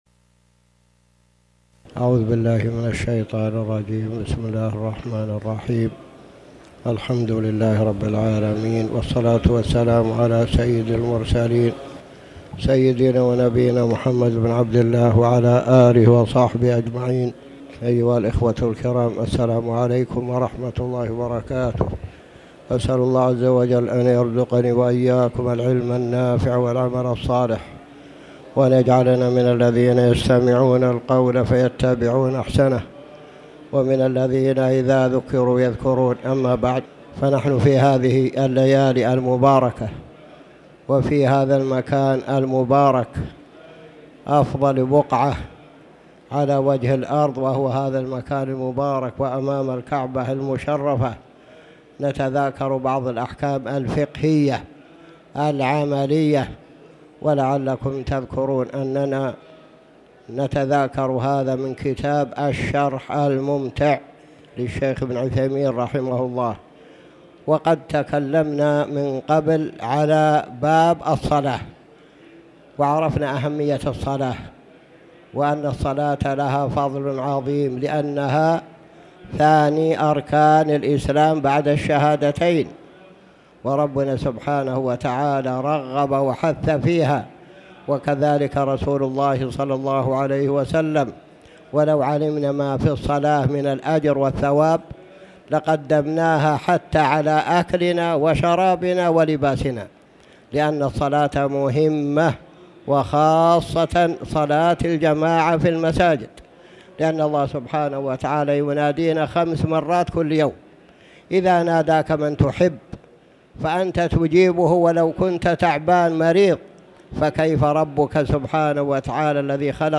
تاريخ النشر ١٠ جمادى الأولى ١٤٣٩ هـ المكان: المسجد الحرام الشيخ